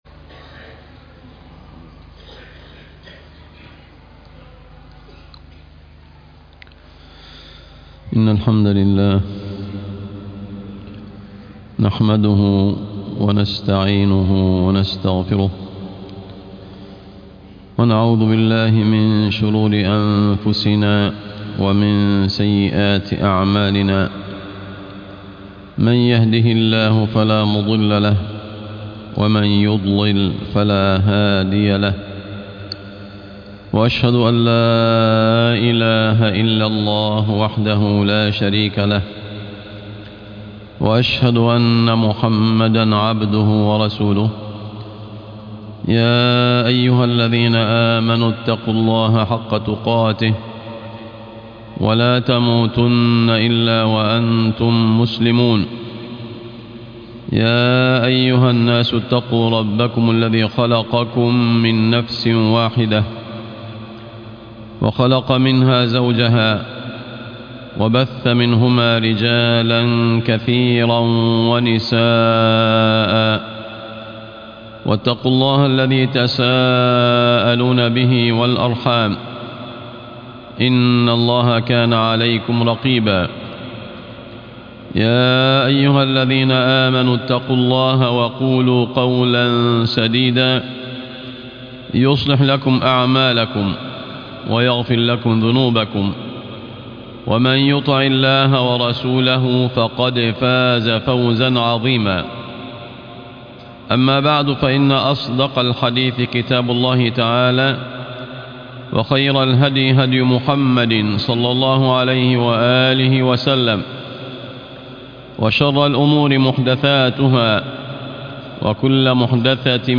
تعظيم السُنة - خطب الجمعة